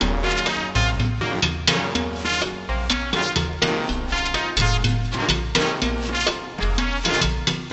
ralenti.wav